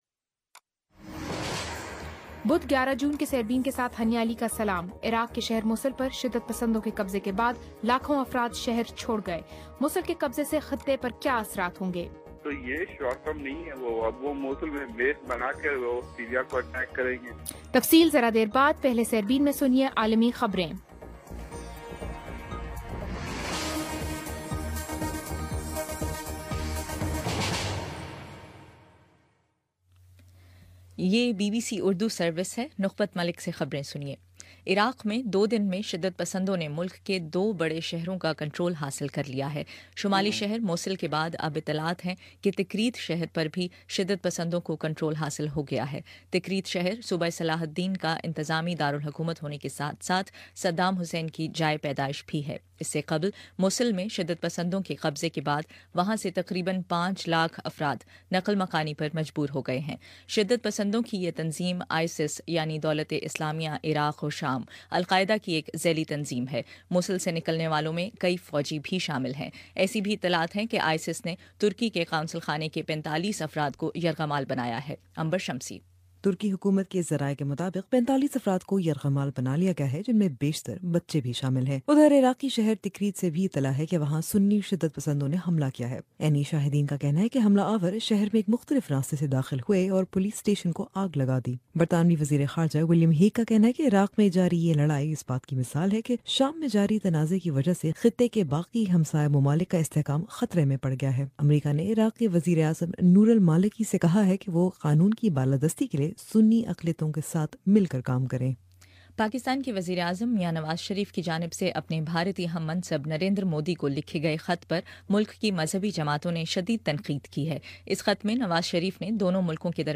بدھ 11 جون کا سیربین ریڈیو پروگرام